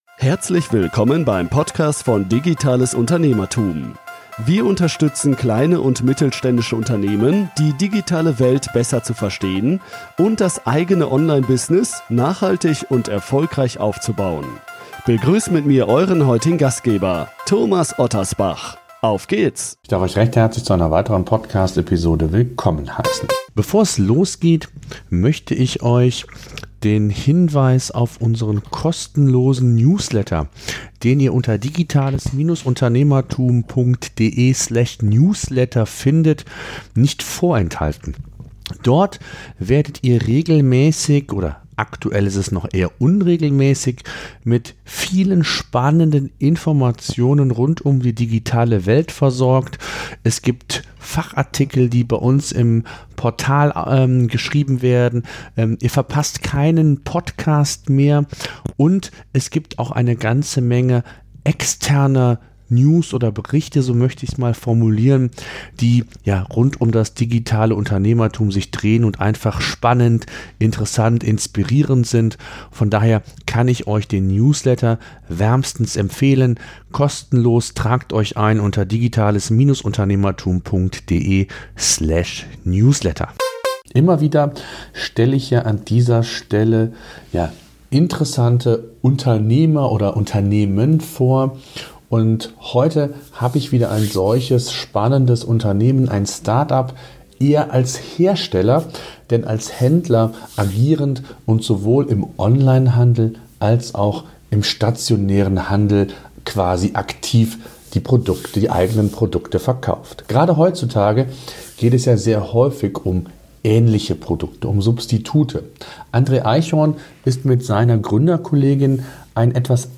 Immer wieder spreche ich an dieser Stelle mit interessanten Unternehmerpersönlichkeiten und Unternehmen.